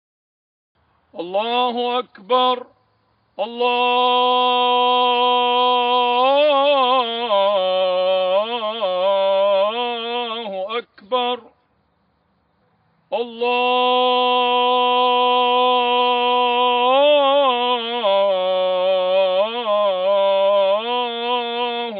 Звук арабского муллы с чтением исламской молитвы